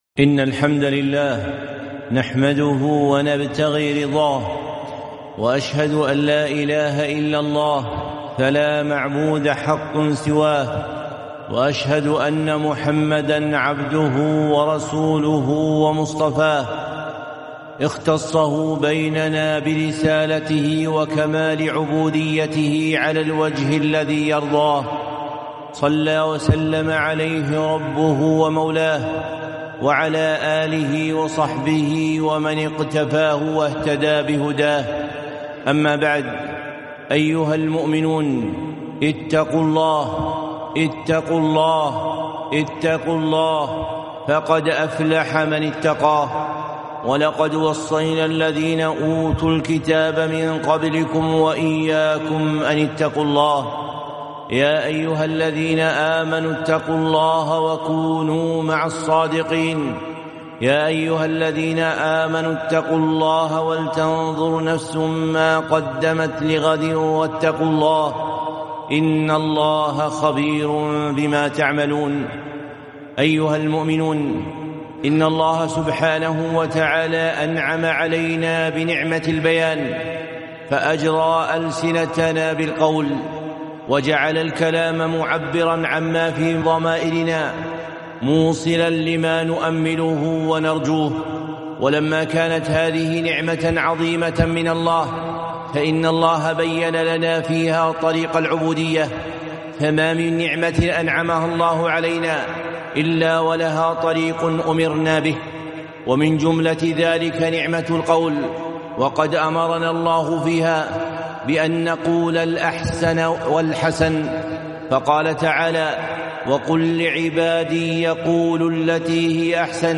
خطبة - الدعاء بخير الجزاء 3-4-1444